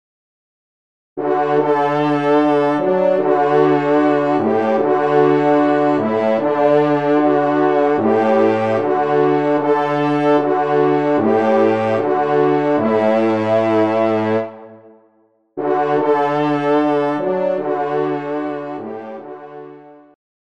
Genre : Fantaisie Liturgique pour quatre trompes
Pupitre de Basse